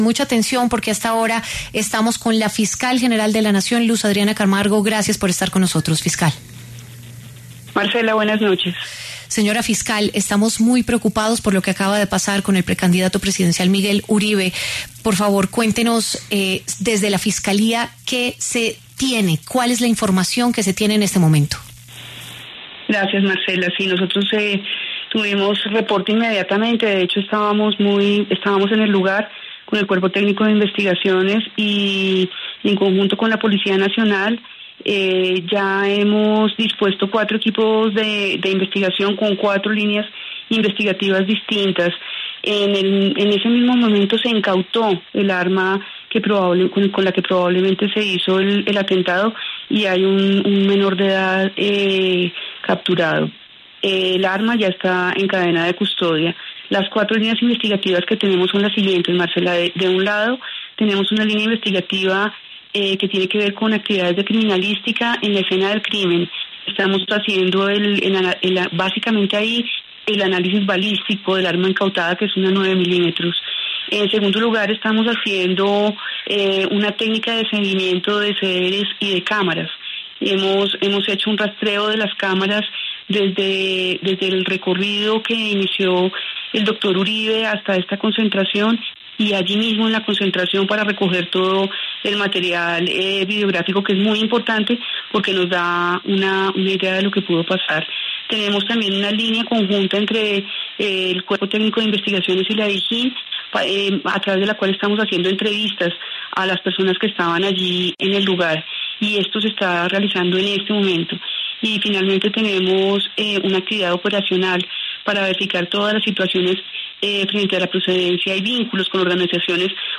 La fiscal general de la Nación, Luz Adriana Camargo, pasó por los micrófonos de W Radio para entregar detalles del atentado del que fue víctima el senador y precandidato presidencial Migue Uribe en la localidad de Fontibón, en Bogotá.